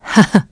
Isolet-Vox-Laugh.wav